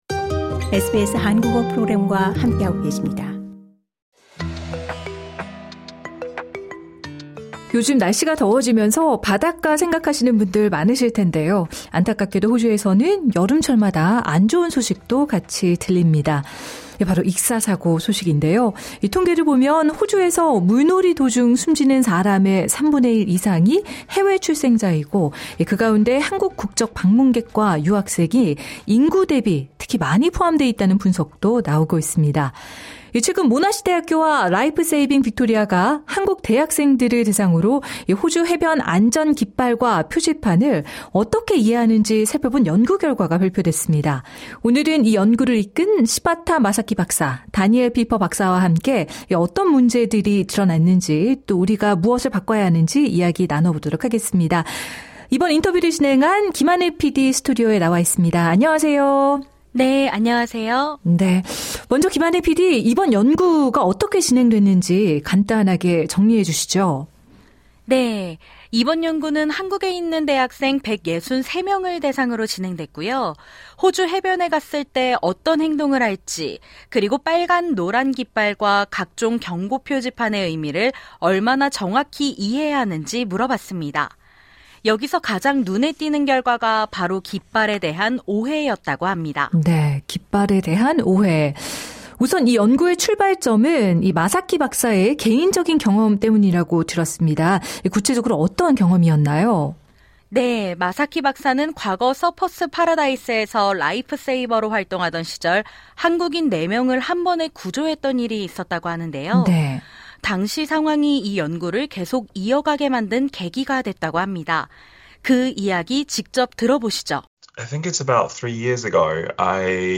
인터뷰: 호주 해변 익사사고, 한국인 비율 높은 이유는?